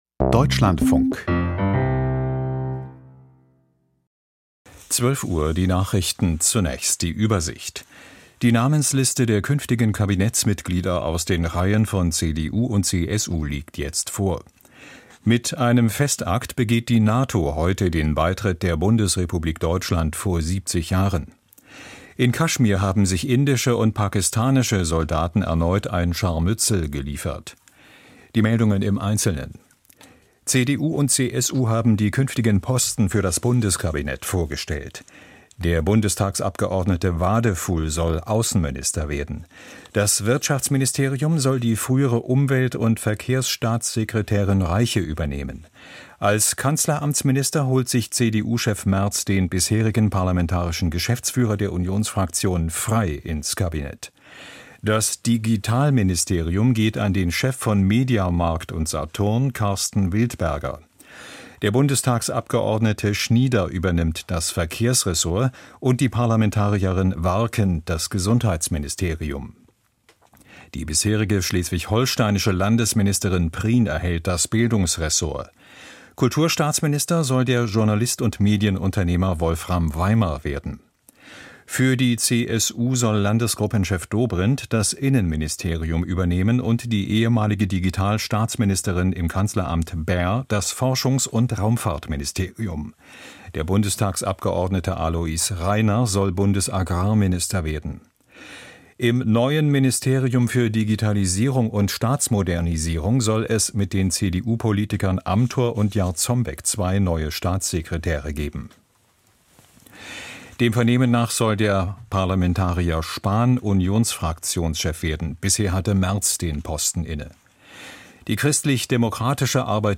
Die Nachrichten ➕ Berlangganan ➕ Berlangganan ✔ Berlangganan ✔ Berlangganan Putar Memutar Bagikan Tandai semua (belum/sudah) diputar ...